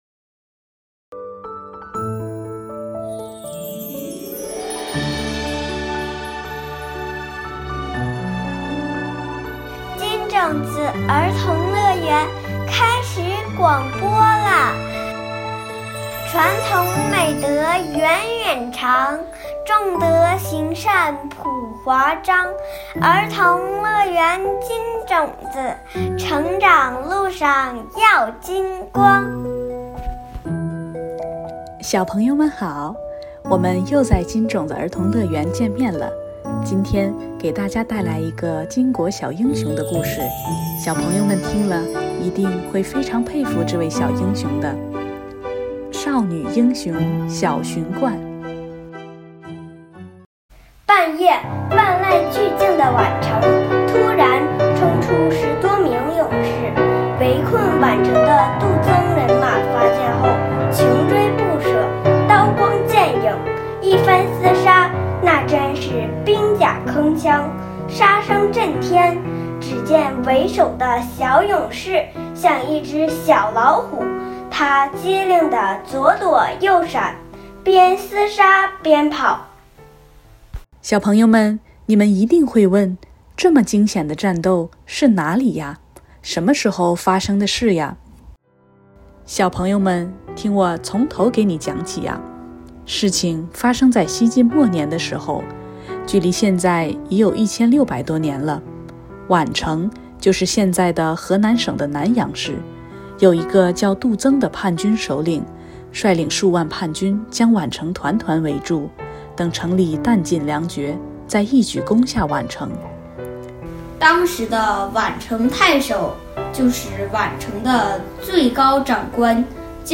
金種子兒童樂園系列廣播故事（音頻）： 第五期《少女英雄小荀灌》